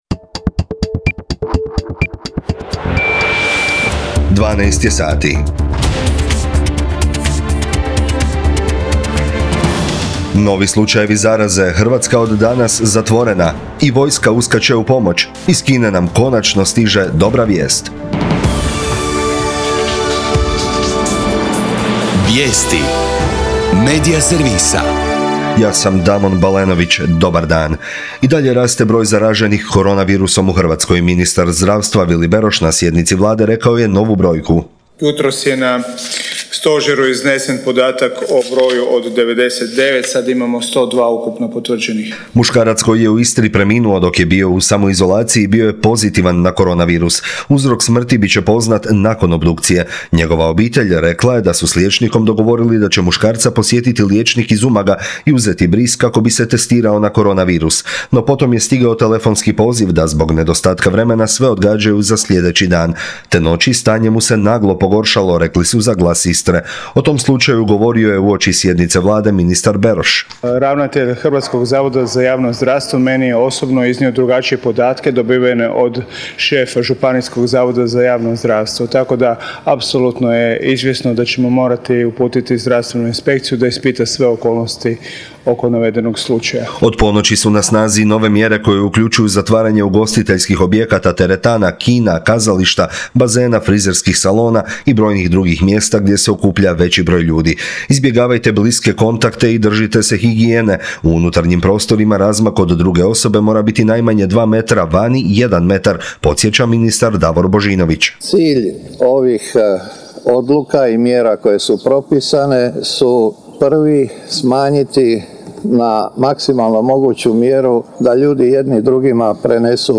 VIJESTI U PODNE